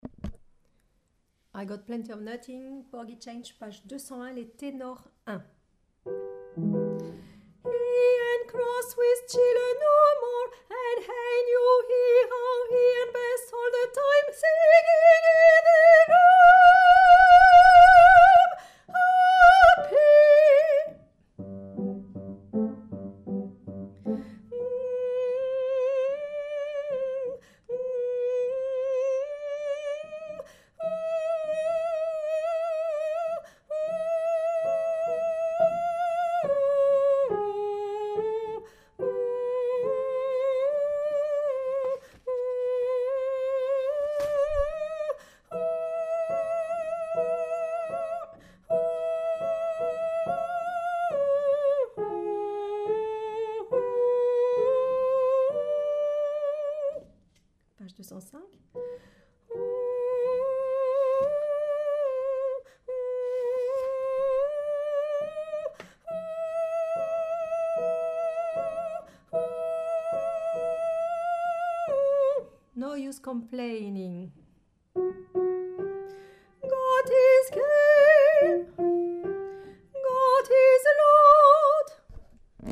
Tenor1
i_got_plenty_Tenor1.mp3